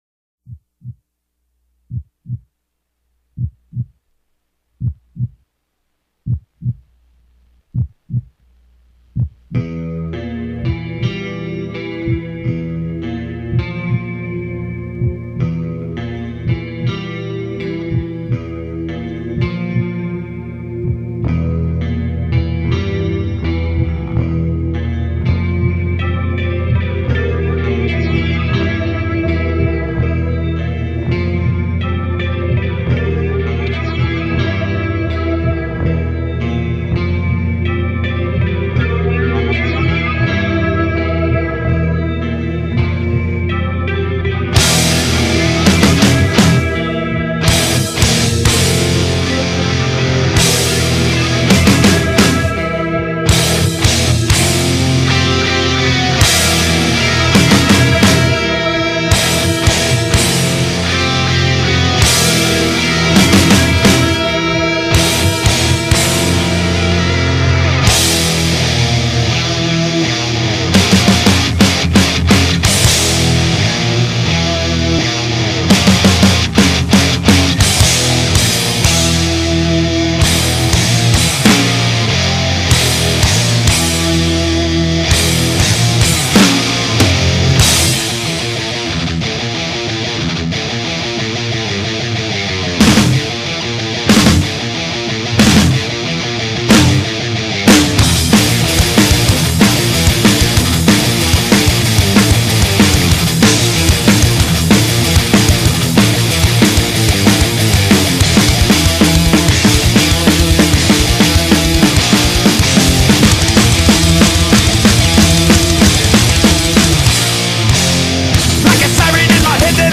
está na cavalgada do riff principal